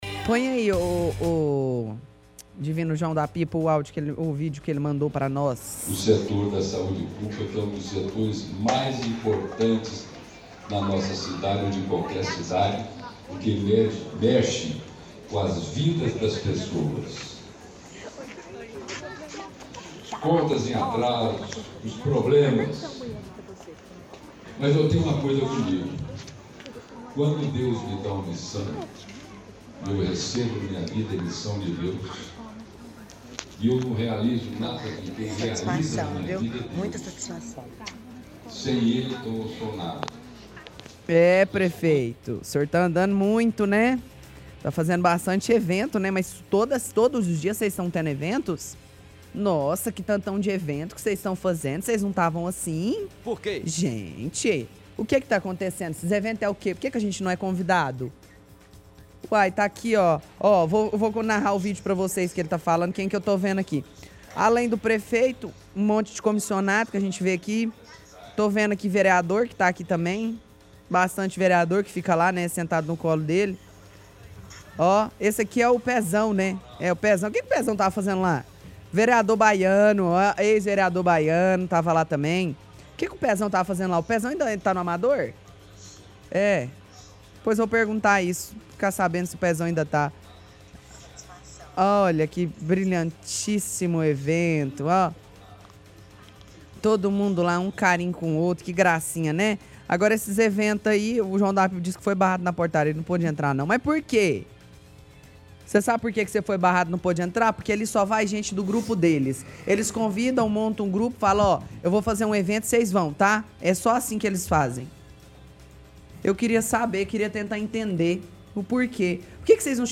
– Ouvinte envia algum vídeo para o programa em que aparece a voz do prefeito falando, aparentemente em algum evento.